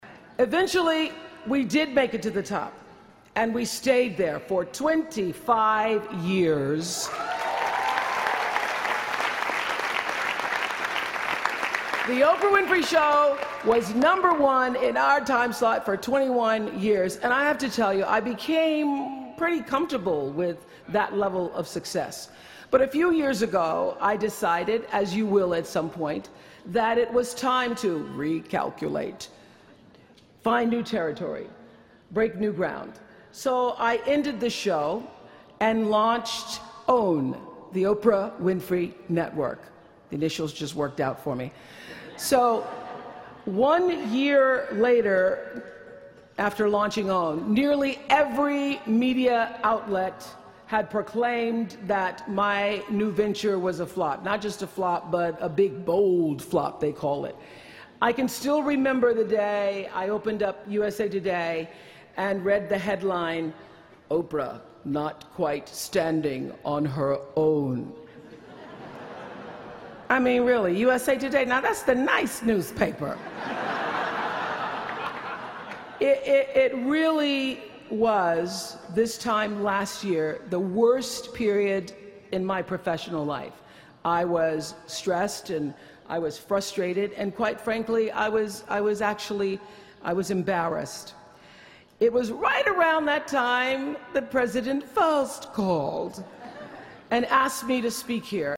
公众人物毕业演讲第352期:奥普拉2013在哈佛大学(4) 听力文件下载—在线英语听力室